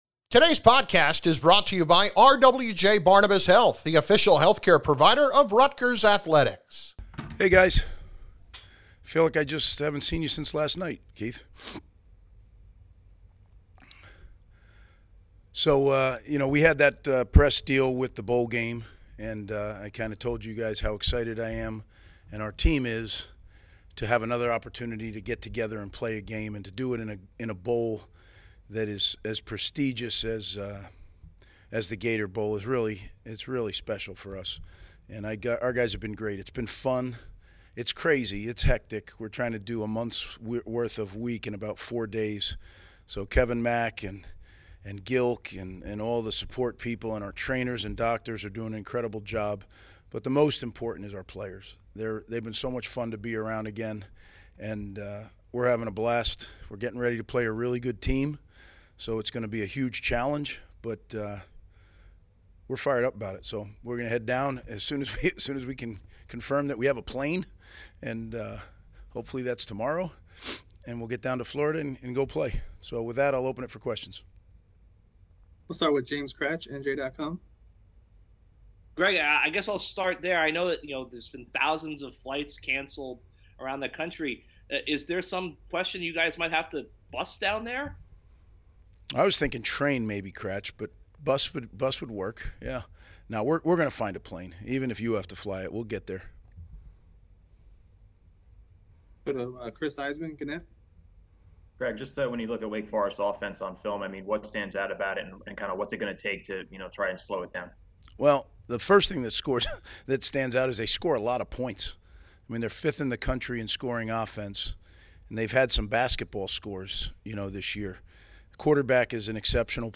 Game Week Press Conference: Greg Schiano - 12/27/21 - Rutgers University Athletics